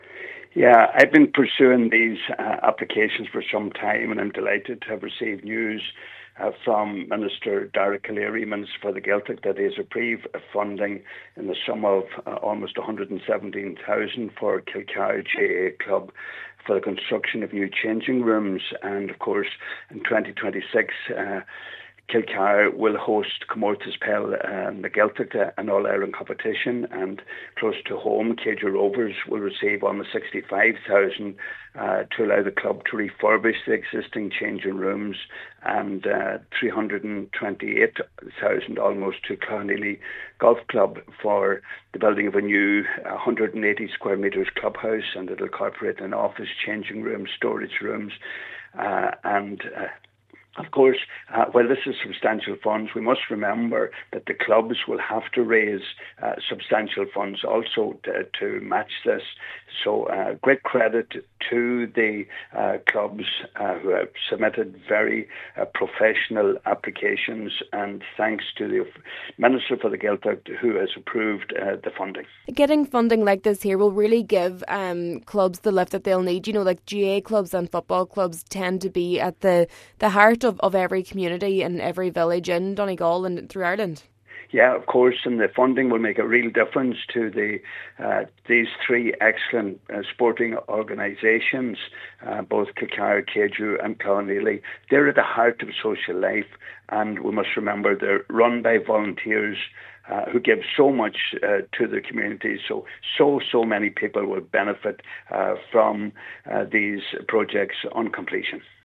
Donegal Deputy Pat the Cope Gallagher has welcomed the funding, saying club will now have to fundraise to match the grants: